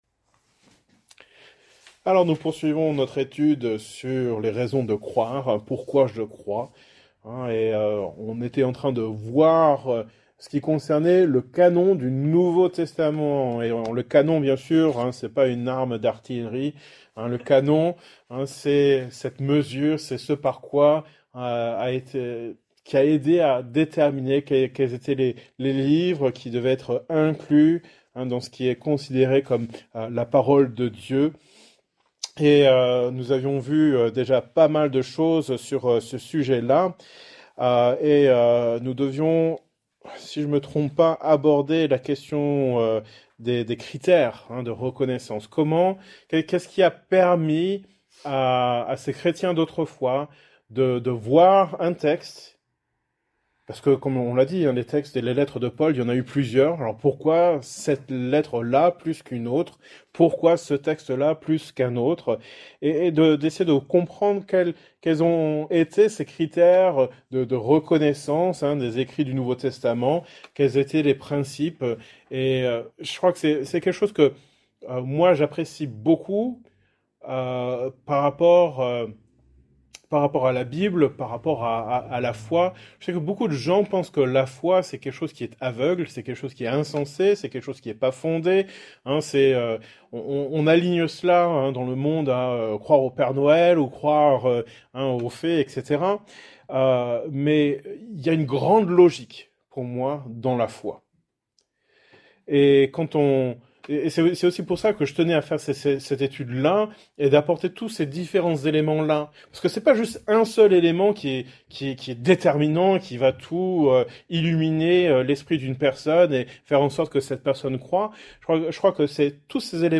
Genre: Etude Biblique